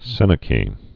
(sĕnə-kē)